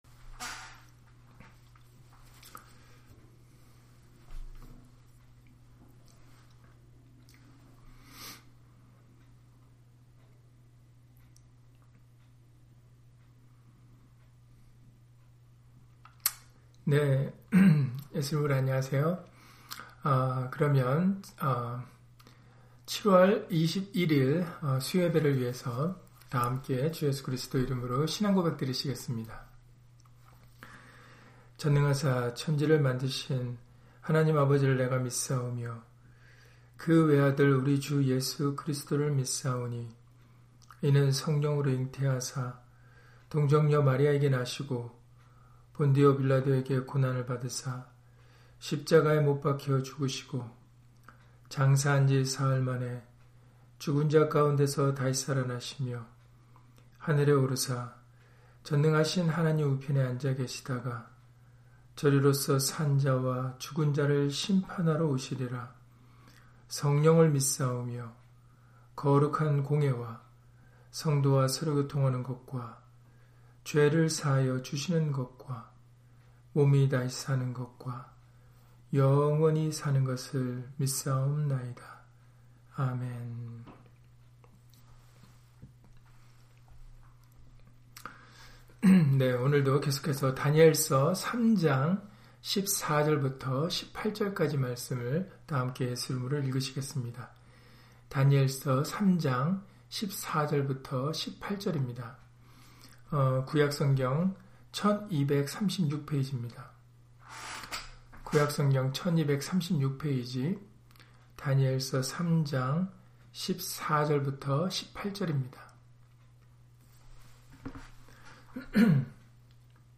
다니엘 3장 14-18절 [그리 아니하실찌라도] - 주일/수요예배 설교 - 주 예수 그리스도 이름 예배당